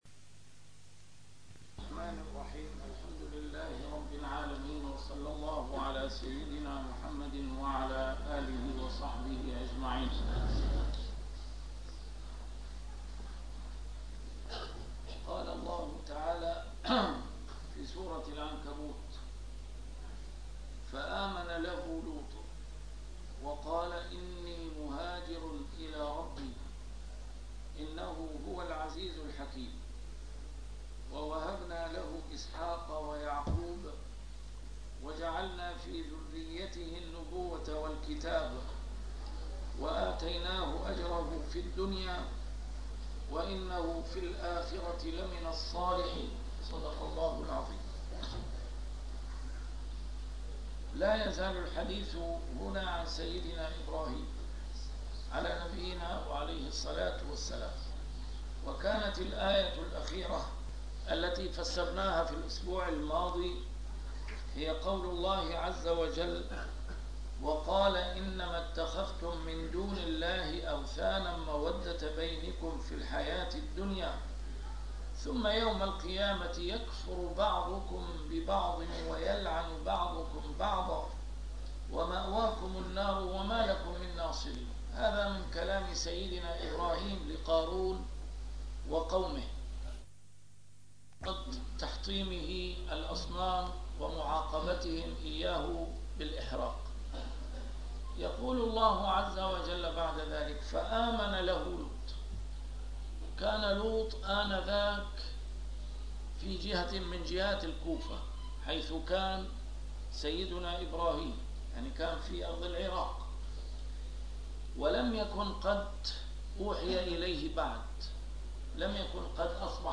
A MARTYR SCHOLAR: IMAM MUHAMMAD SAEED RAMADAN AL-BOUTI - الدروس العلمية - تفسير القرآن الكريم - تسجيل قديم - الدرس 299: العنكبوت 26-27